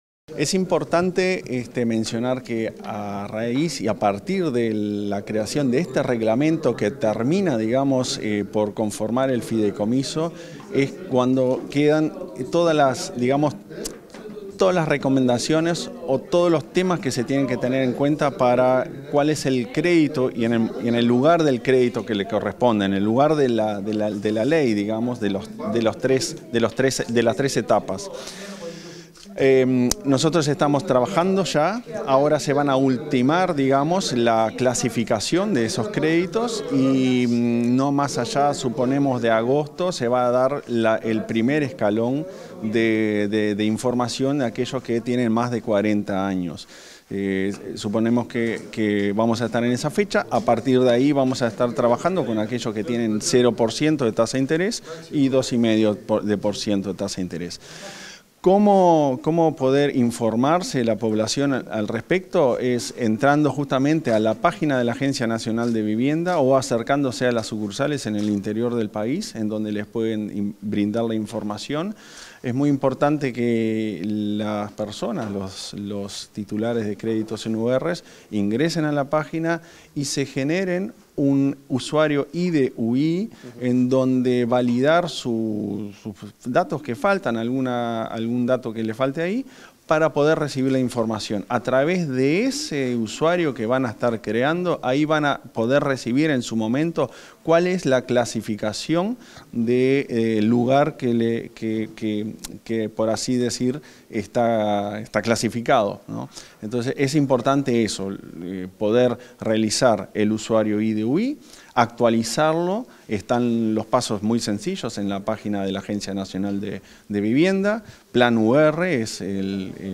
Declaraciones a la prensa del presidente de la ANV, Klaus Mill
Declaraciones a la prensa del presidente de la ANV, Klaus Mill 03/07/2024 Compartir Facebook X Copiar enlace WhatsApp LinkedIn Tras participar en una conferencia de prensa acerca de la aprobación del reglamento del fideicomiso interno para deudores en unidades reajustables, este 3 de julio, el presidente de la Agencia Nacional de Vivienda (ANV), Klaus Mill, realizó declaraciones a la prensa.
Mill prensa.mp3